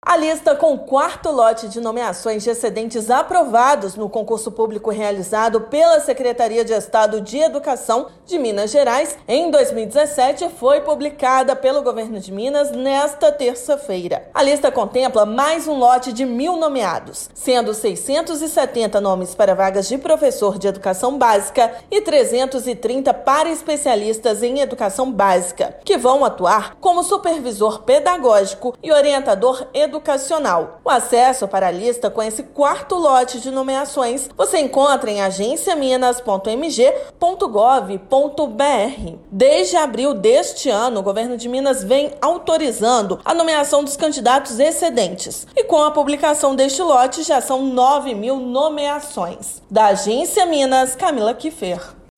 São mais mil nomeações para cargos de Professor de Educação Básica (PEB) e Especialista em Educação Básica (EEB). Ouça a matéria de rádio.